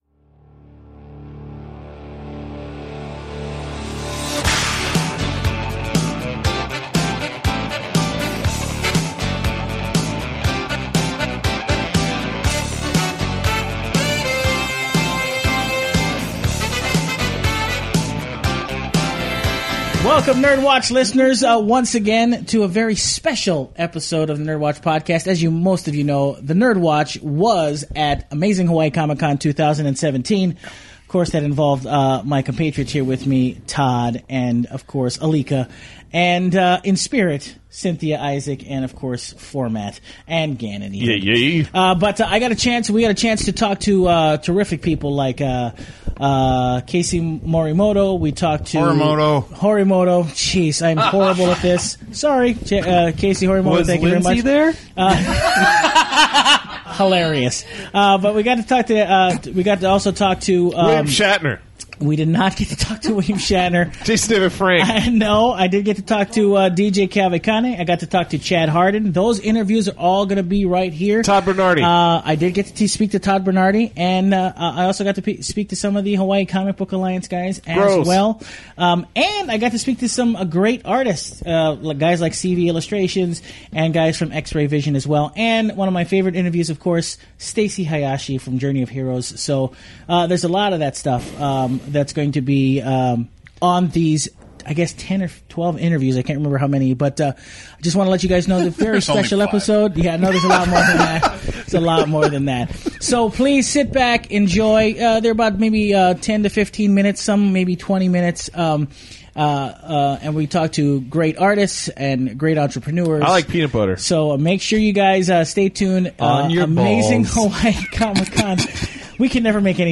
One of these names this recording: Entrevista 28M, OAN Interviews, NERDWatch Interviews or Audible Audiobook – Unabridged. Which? NERDWatch Interviews